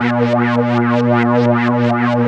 3100 AP  A#3.wav